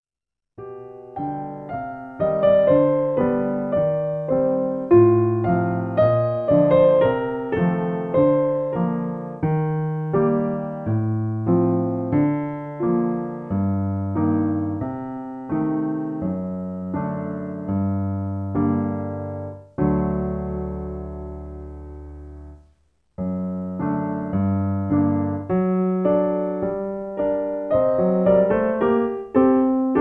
In G. Piano Accompaniment